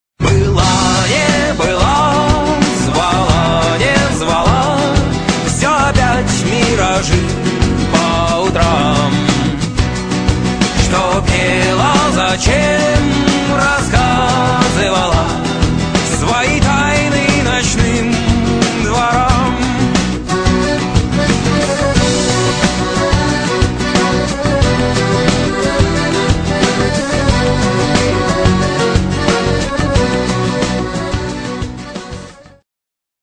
реалтоны рок